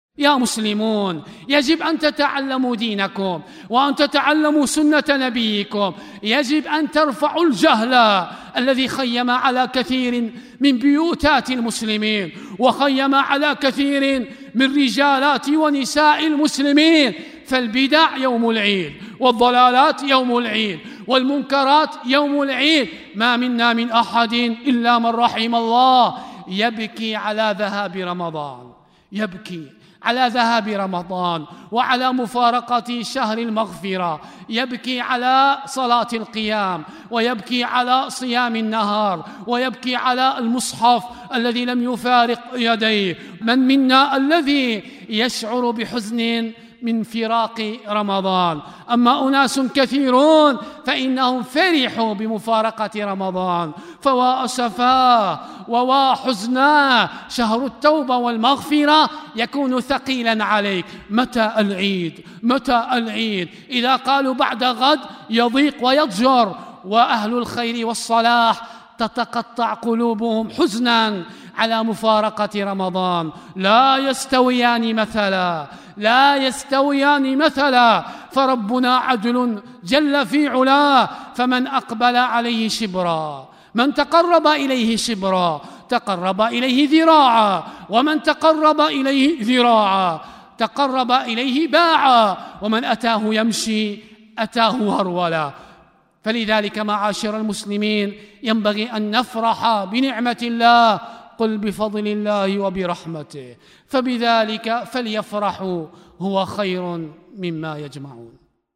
كلمة منتقاة من خطبة عيد الفطر لعام 1442 هـ